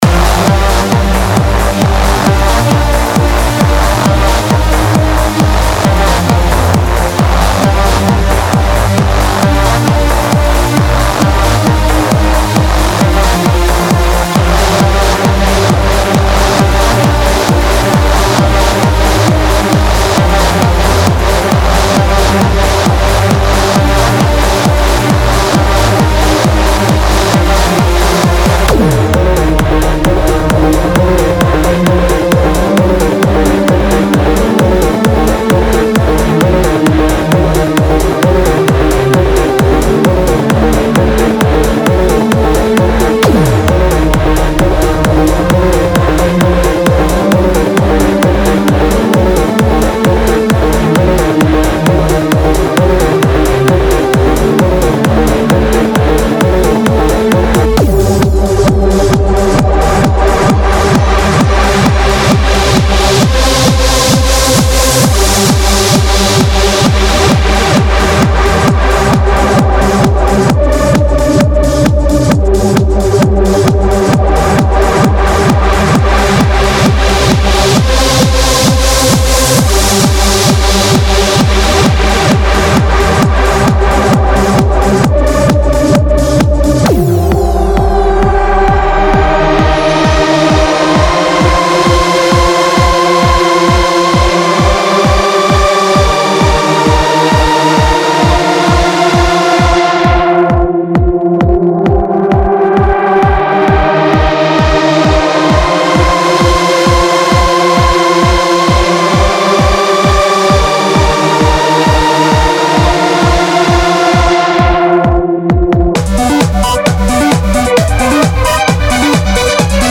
ACID LOOPS LIBRARY
address latest trends in Trance
No Fluff, No Fillers just pure Trance Energy.